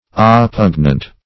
Oppugnant synonyms, pronunciation, spelling and more from Free Dictionary.
Search Result for " oppugnant" : The Collaborative International Dictionary of English v.0.48: Oppugnant \Op*pug"nant\, a. [L. oppugnans, p. pr. of oppugnare.
oppugnant.mp3